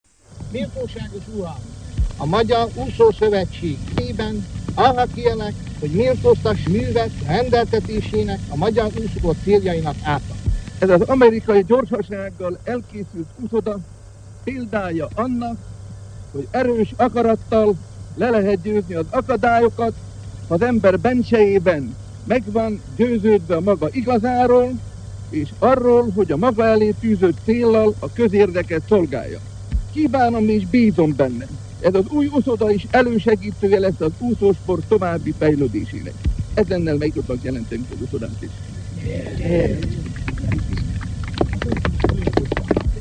Kelemen Kornél, az OTT elnöke 1937-ben megnyitja az új margitszigeti sportuszodát.